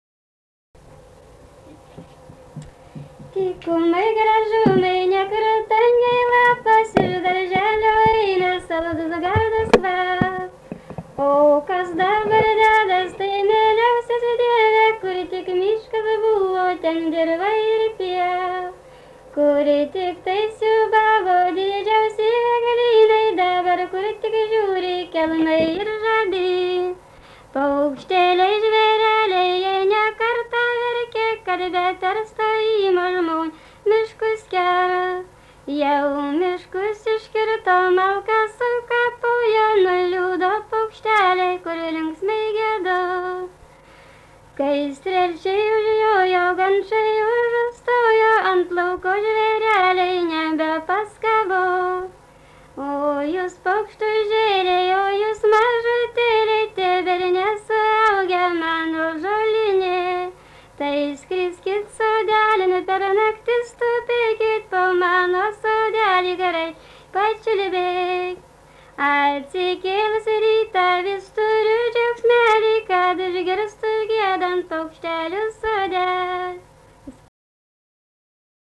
daina, šeimos
Atlikimo pubūdis vokalinis